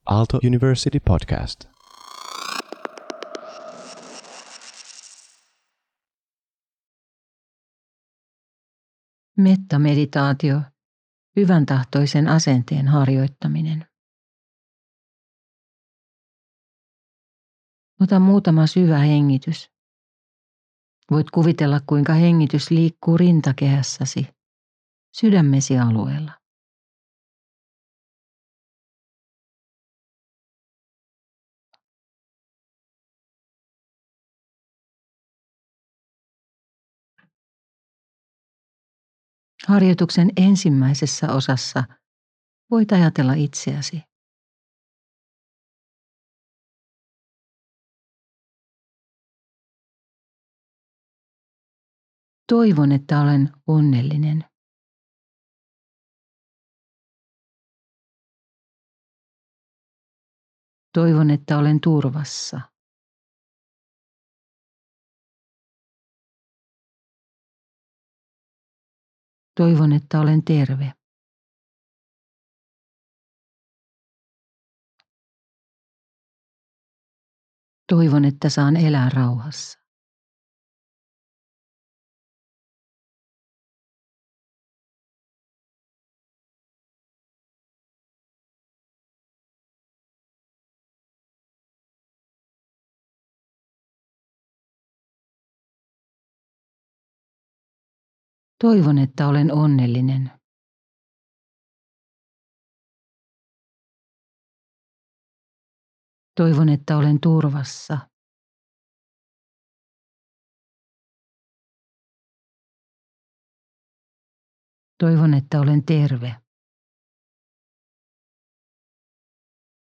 Merita-Mindfulness-2024-FI-metta-meditaatio.mp3